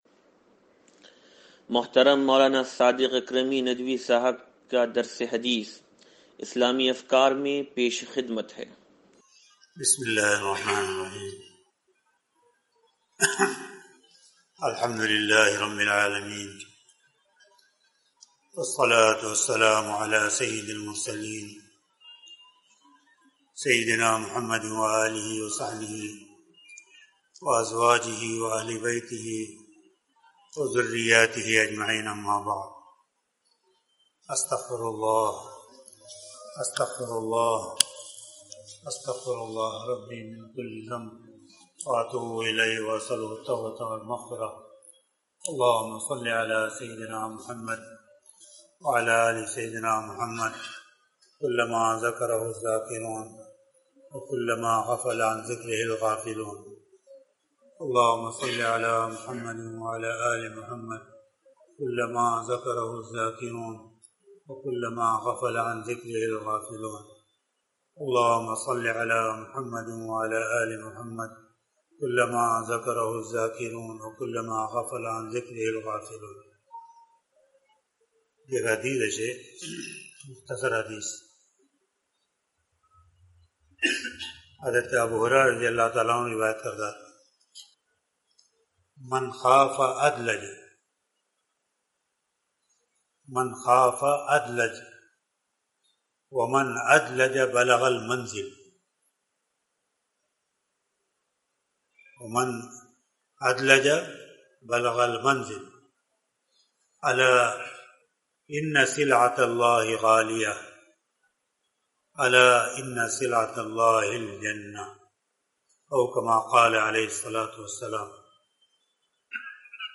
درس حدیث نمبر 0514